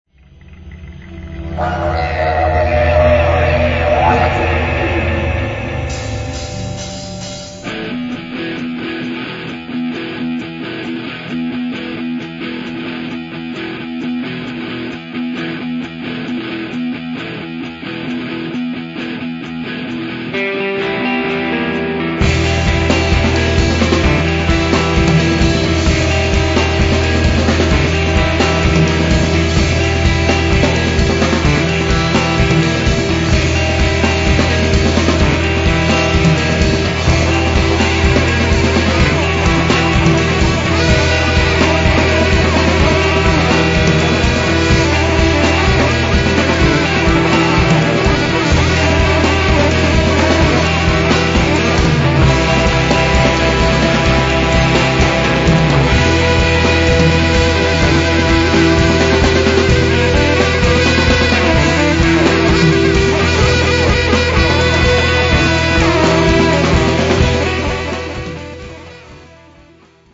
若手ポスト・ロックバンドのホープ